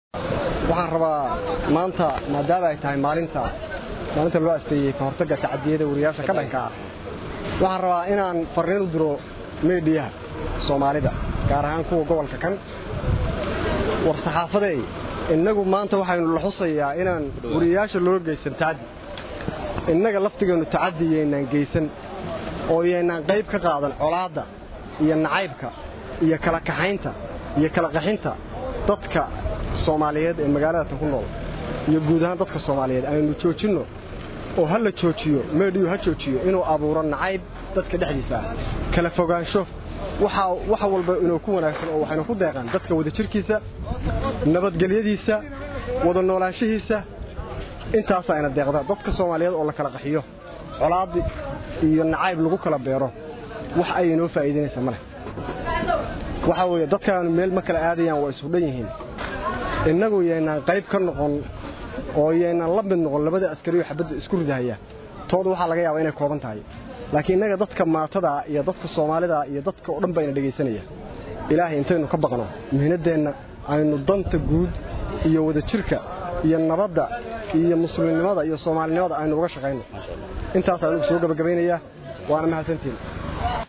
Dhagayso Qaar ka mid ah wariyayaasha oo ka hadlay kulankaas.